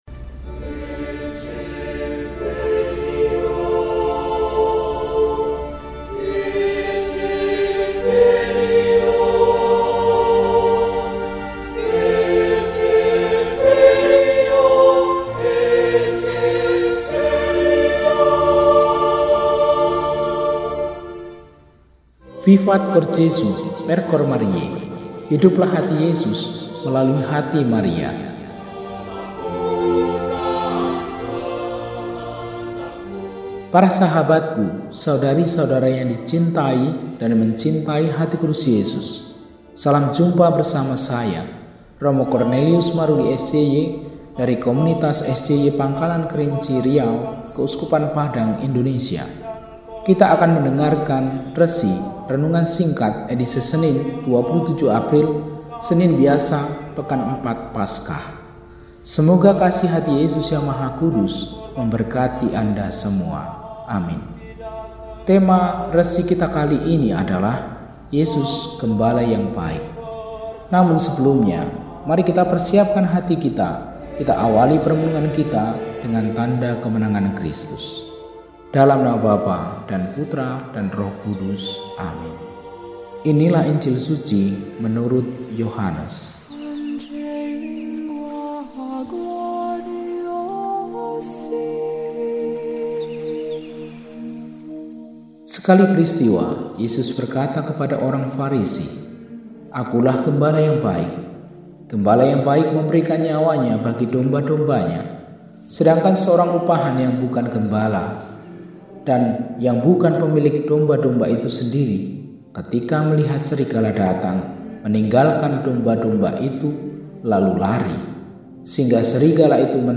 Senin, 27 April 2026 – Hari Biasa Pekan IV Paskah – RESI (Renungan Singkat) DEHONIAN